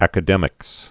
(ăkə-dĕmĭks)